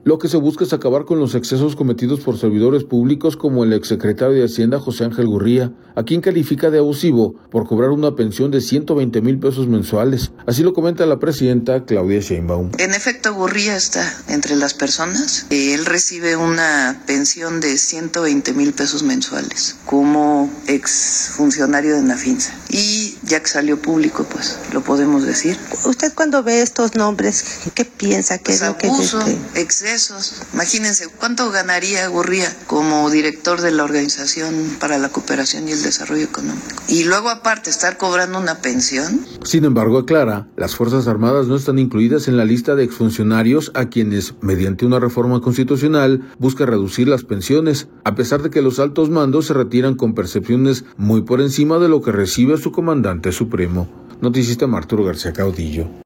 Lo que se busca es acabar con los excesos cometidos por servidores públicos como el ex secretario de Hacienda, José Ángel Gurría, a quien califica de abusivo, por cobrar una pensión de 120 mil pesos mensuales, así lo comenta presidenta Claudia Sheinbaum.